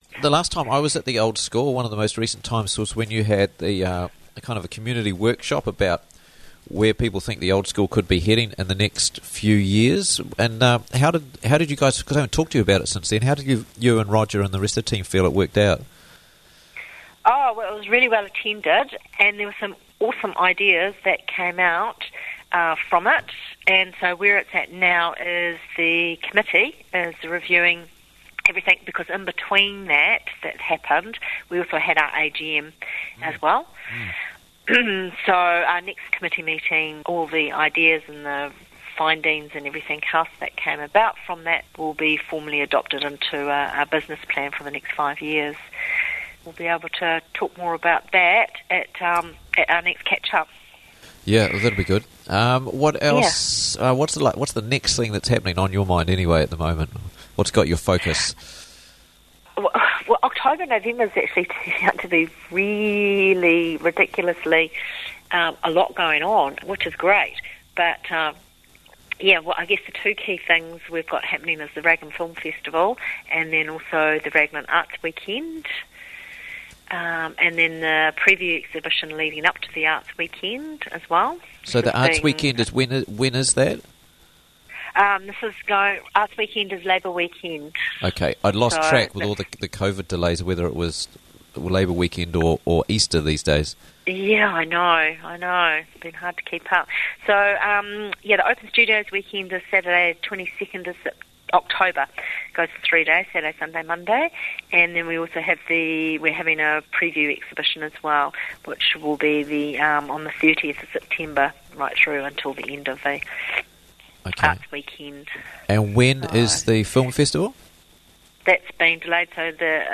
on the line to tell us about the many, many events and projects they're working on at the moment